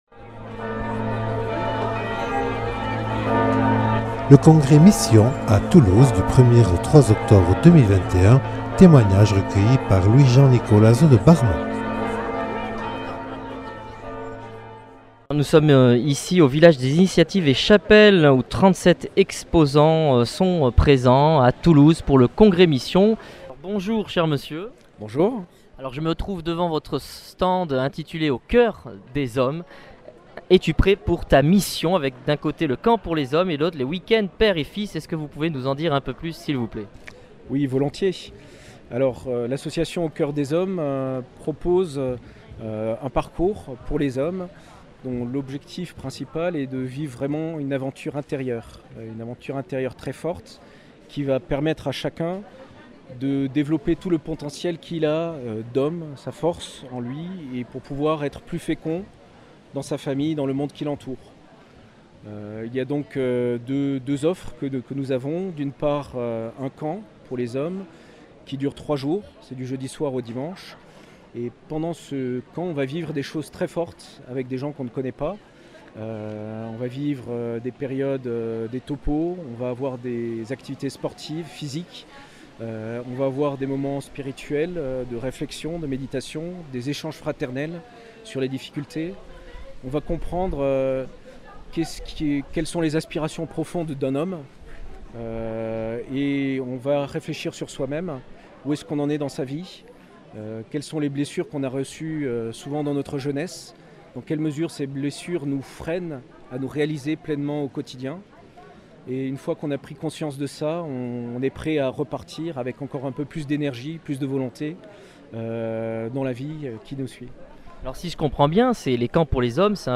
Reportage réalisé au congrès Mission à Toulouse du 1er au 3 octobre 2021.